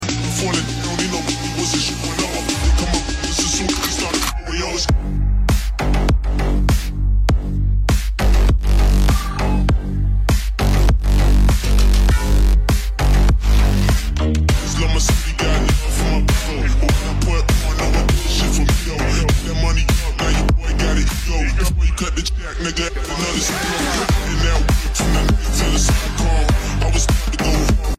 Ford Mustang Upgraded with JBL Stadium Speakers Separates